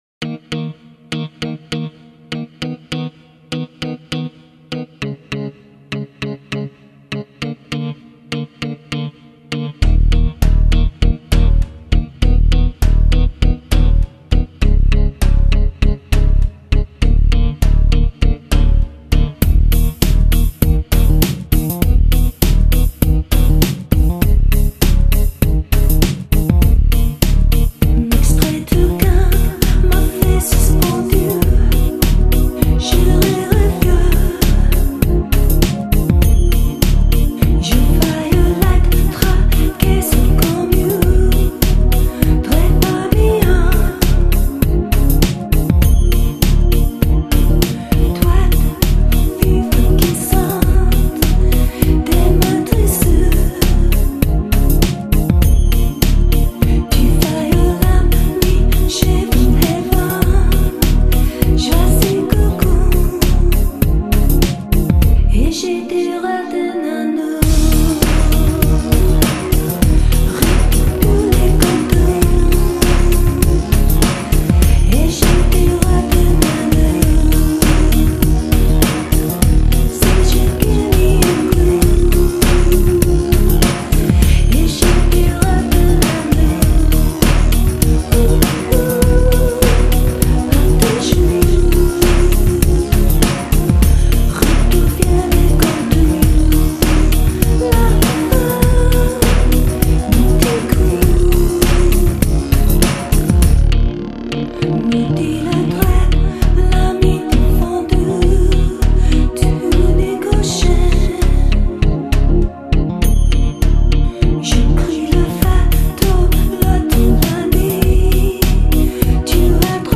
演唱歌手：新世纪音乐